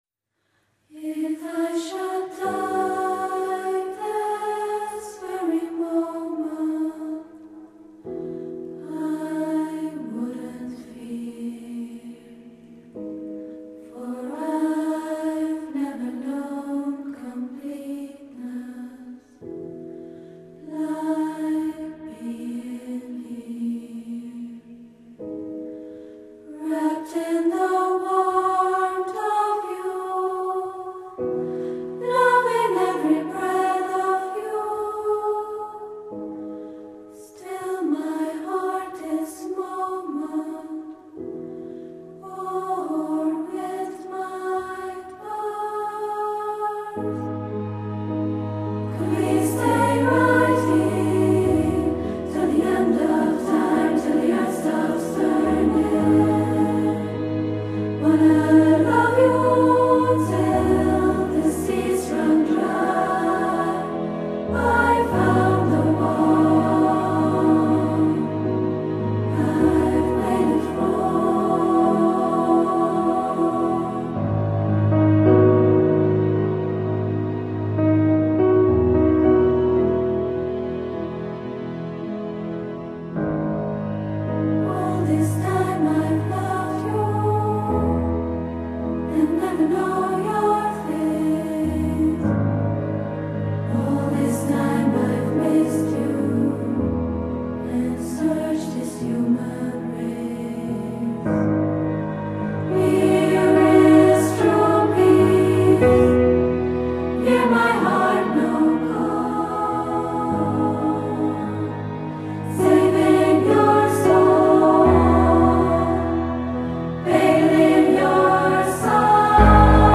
Music Review